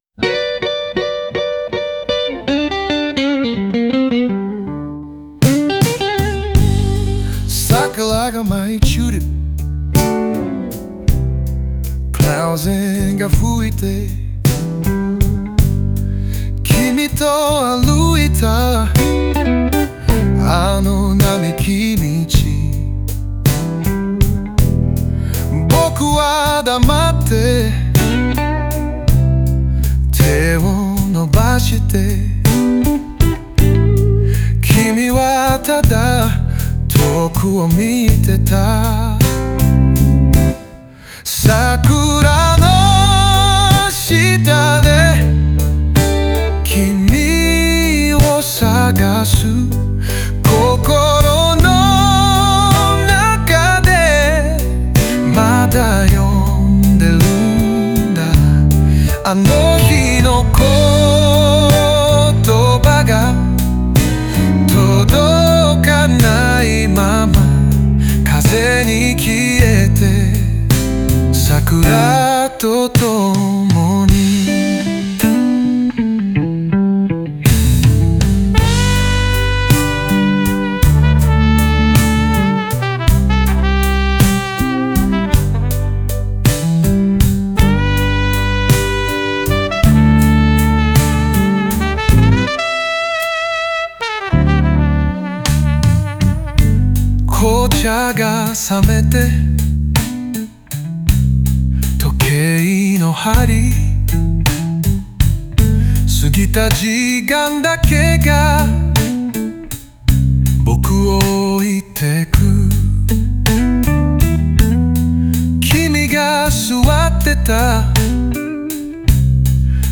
オリジナル曲♪
桜が舞い散る風景が、別れた人への未練や思い出を象徴しており、ブルースのリズムに乗せて、失われた時間への哀愁を表現。